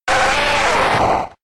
Cri de Racaillou K.O. dans Pokémon X et Y.